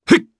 Gladi-Vox_Jump_jp.wav